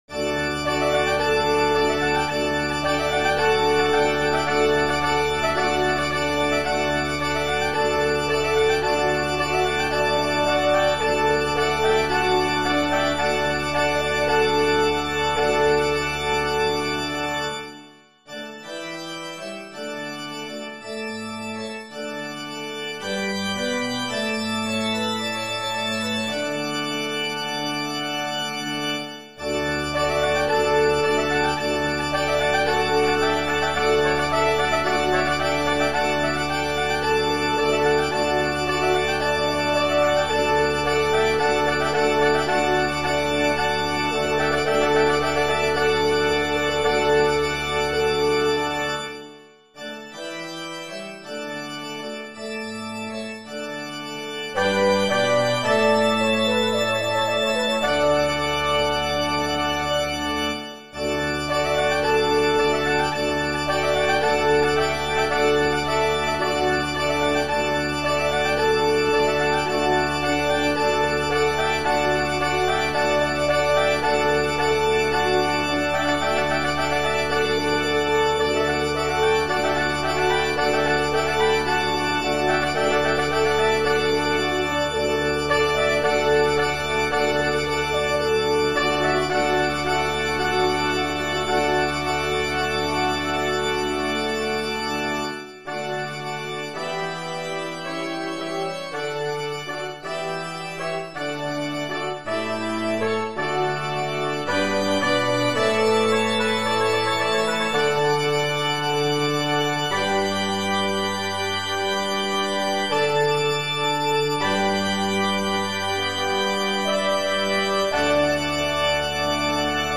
• Brass Quintet and Organ